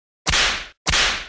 دانلود آهنگ شلاق 3 از افکت صوتی اشیاء
جلوه های صوتی
دانلود صدای شلاق 3 از ساعد نیوز با لینک مستقیم و کیفیت بالا